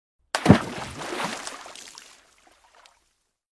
Sonneries » Sons - Effets Sonores » plouf bruitage